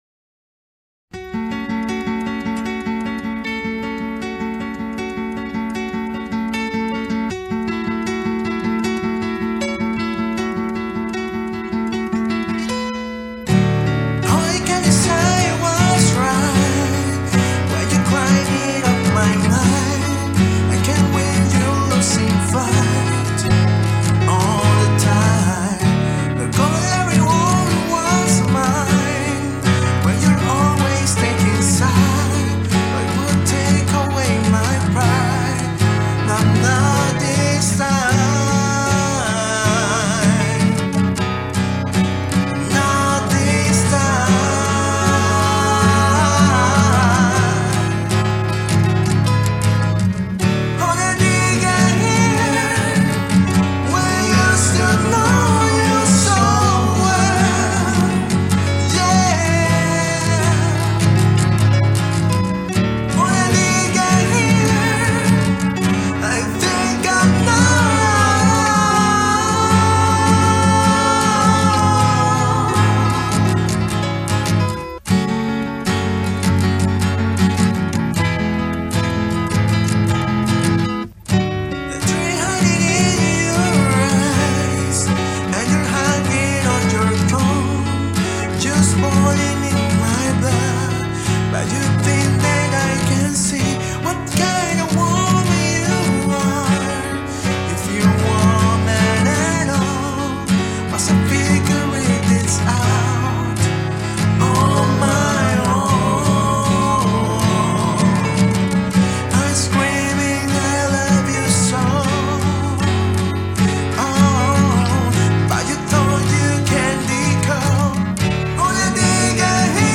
Cover acustico